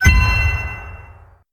gd_coin.wav